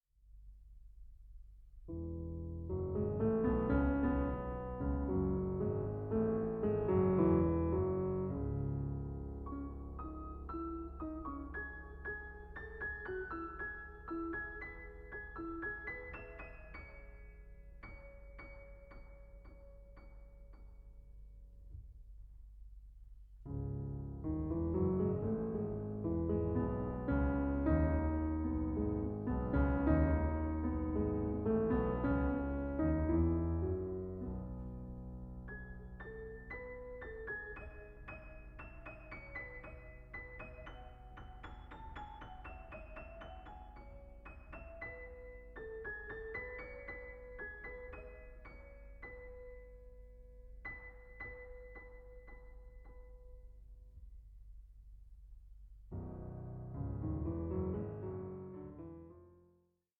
16) No. 8, in F-Sharp Minor: Fugue 7:16